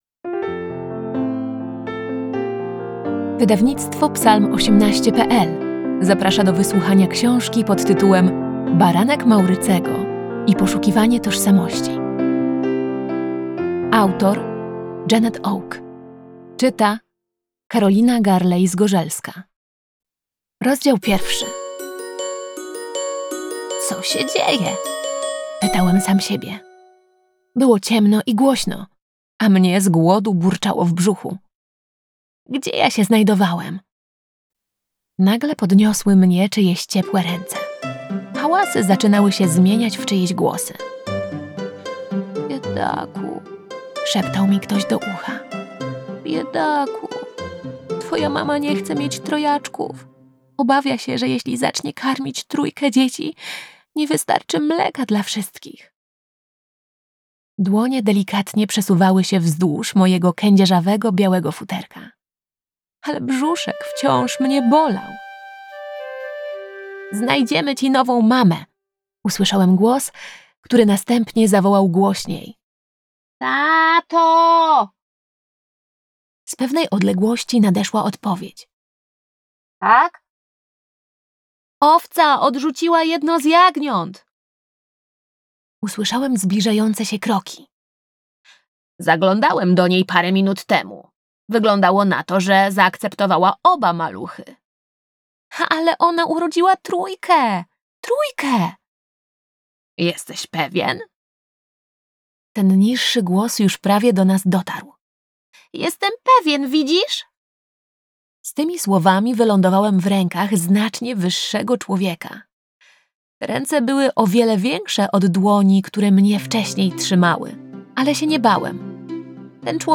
Baranek Maurycego i poszukiwanie tożsamości - Audiobook